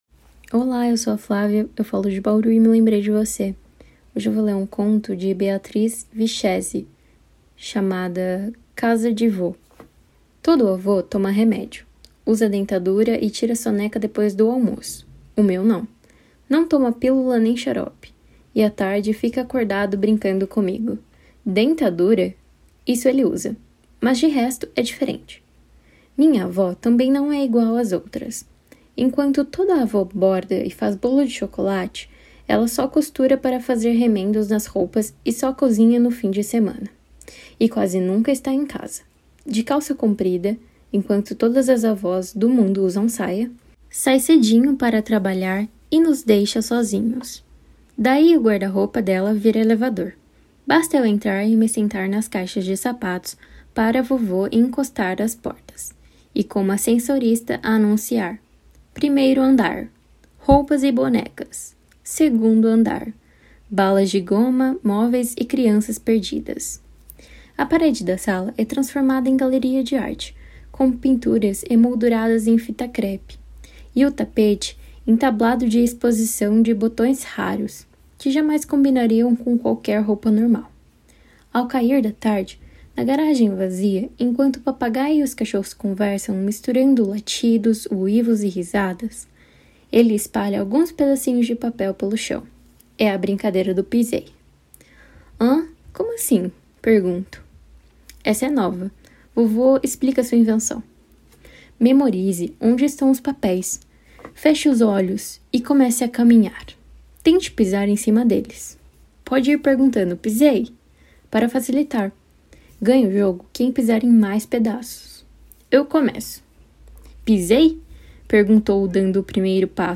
Conto Português